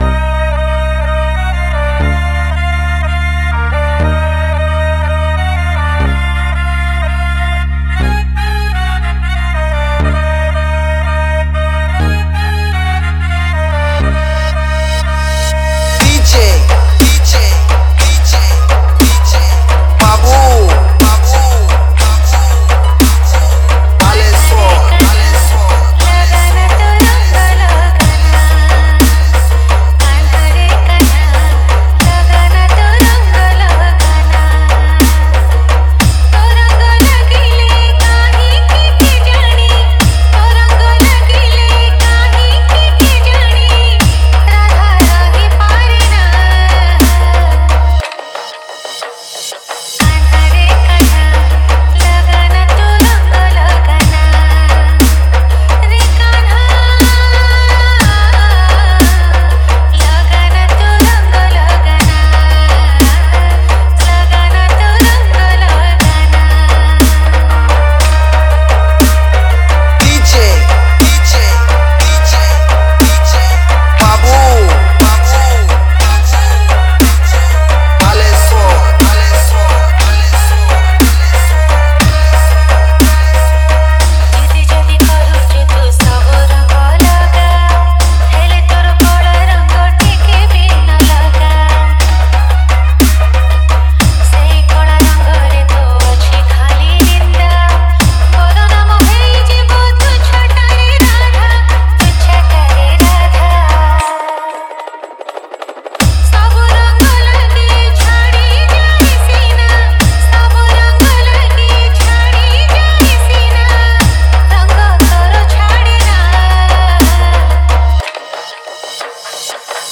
Category:  Odia Bhajan Dj 2020